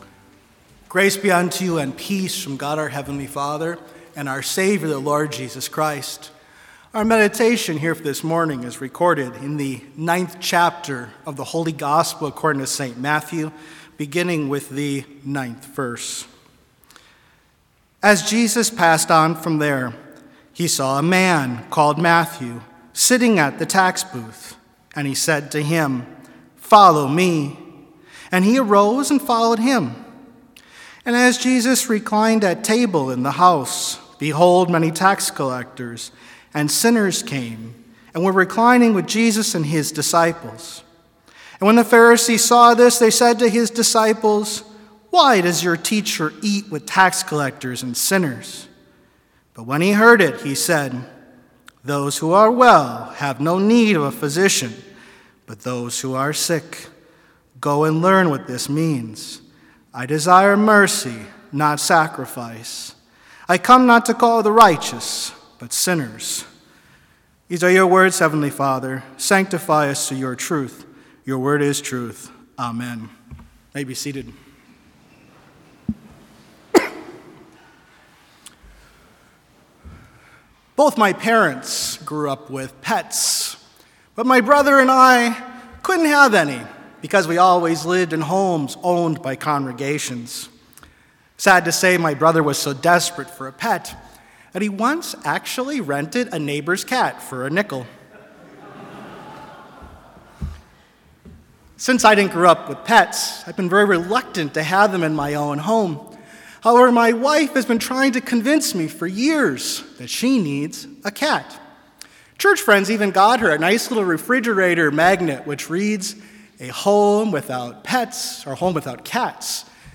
Complete service audio for Chapel - February 28, 2022